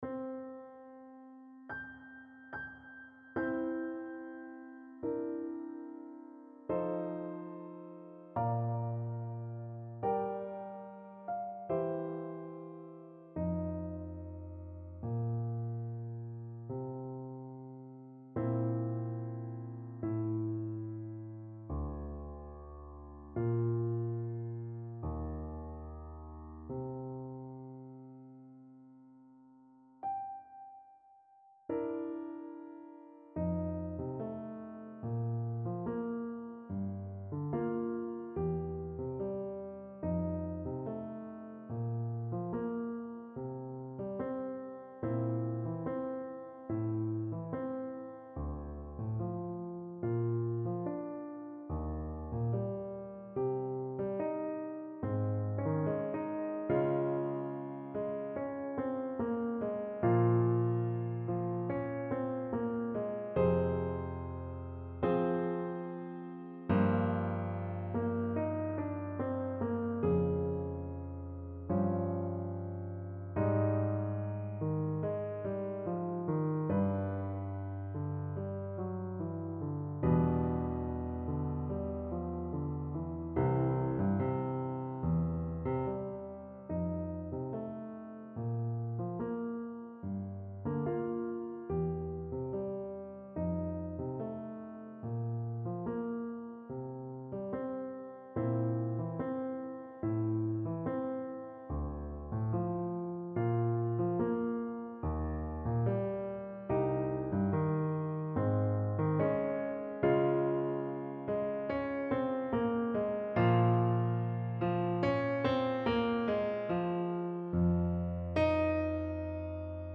Somewhere Piano Backing | Ipswich Hospital Community Choir
Somewhere-Piano-Backing.mp3